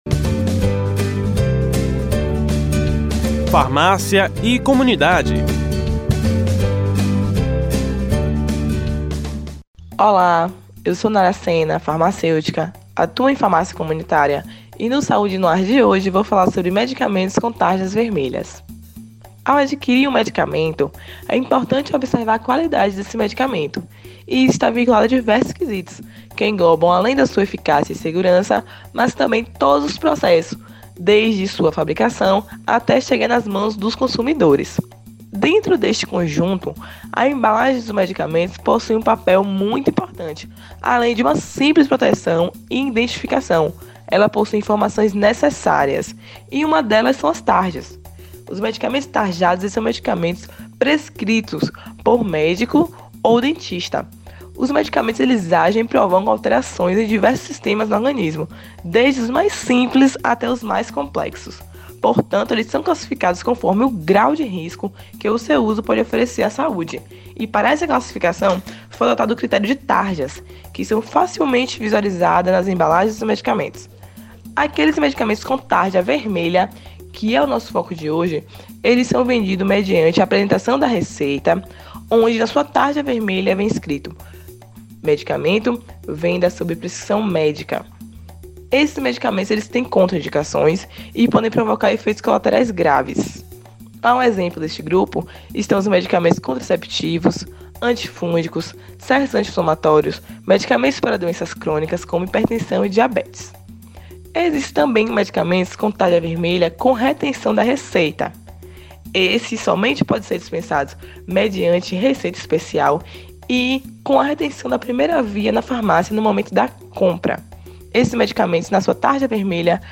O quadro é exibido toda terça-feira no Programa Saúde No Ar veiculado pela Rede Excelsior de Comunicação: AM 840, FM 106.01, Recôncavo AM 1460 e Rádio Saúde no ar / Web.